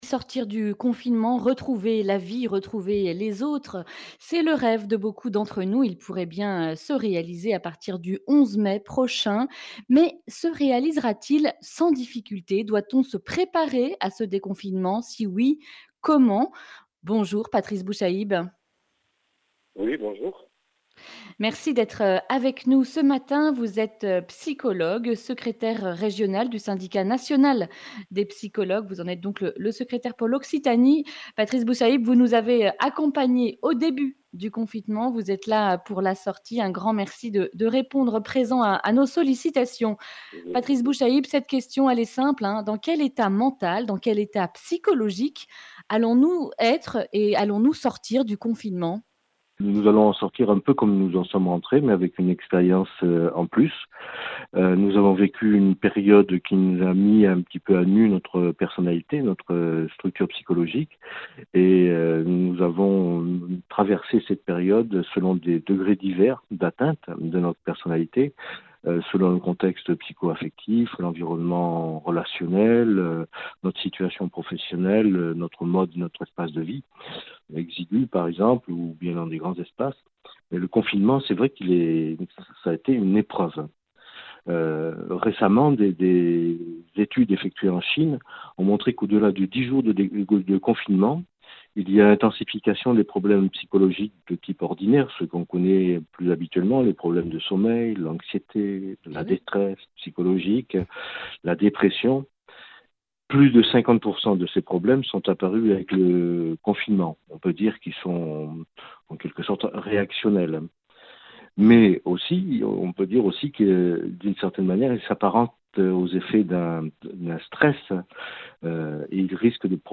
Accueil \ Emissions \ Information \ Régionale \ Le grand entretien \ Dans quel état psychologique sortirons-nous du confinement ?